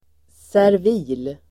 Ladda ner uttalet
servil.mp3